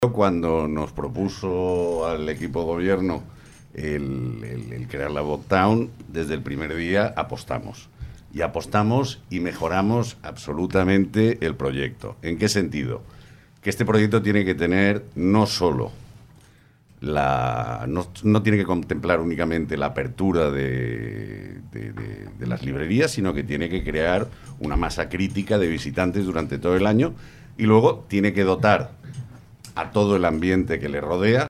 El debat electoral de Calonge i Sant Antoni 2023 de Ràdio Capital de l'Empordà ha enfrontat els principals candidats a ser alcalde al municipi